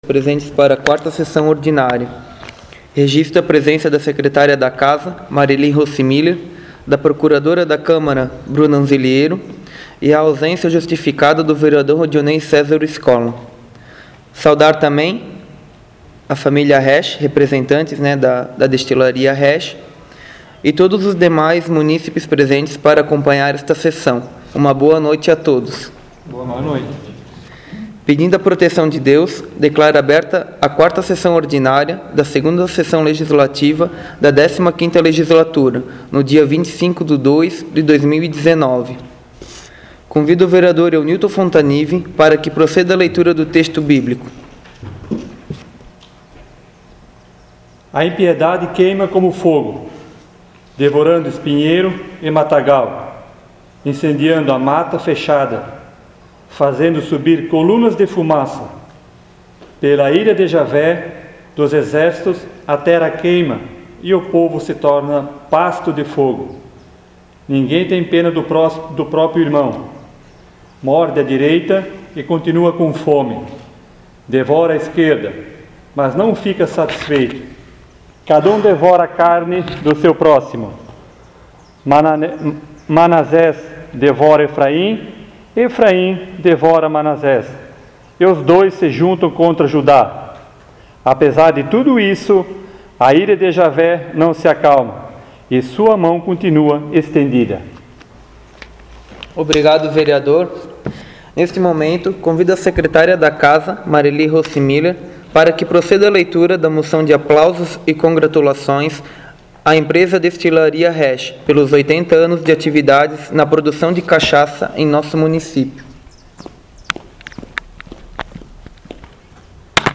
4ª Sessão Ordinária 2019